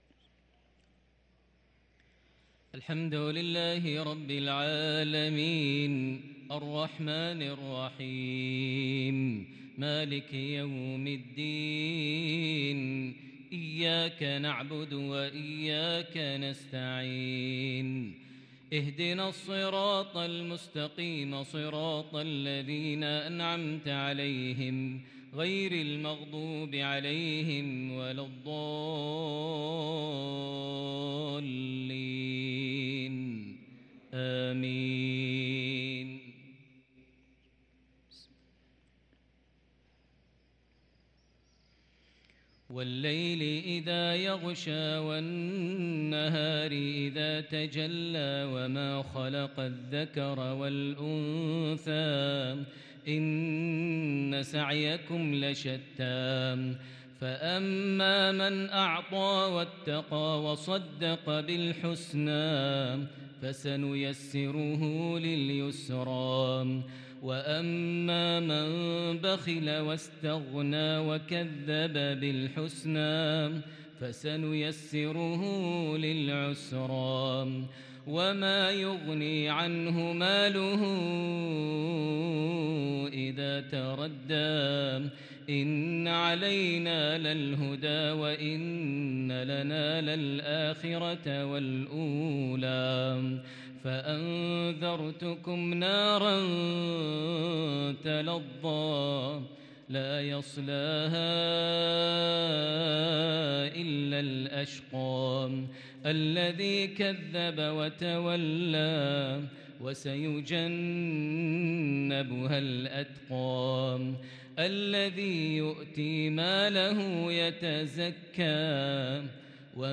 صلاة المغرب للقارئ ماهر المعيقلي 29 ربيع الآخر 1444 هـ
تِلَاوَات الْحَرَمَيْن .